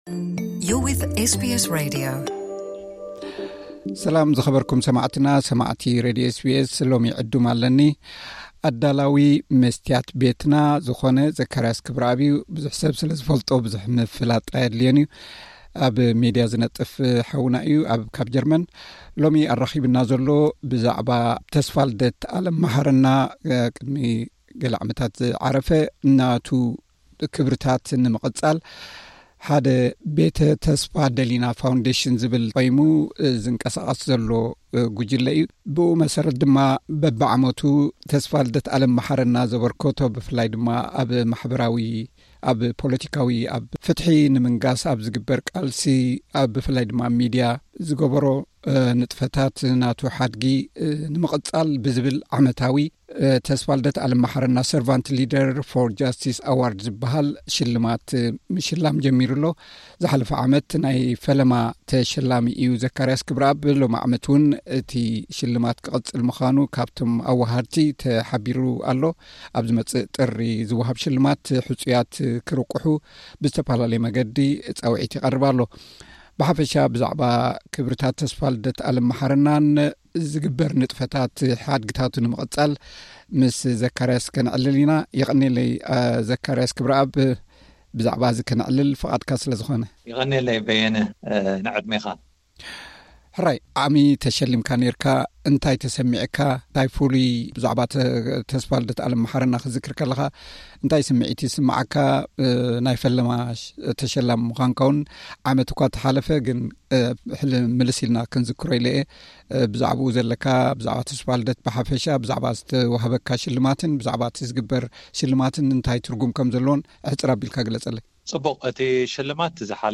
ቃለ መሕትት።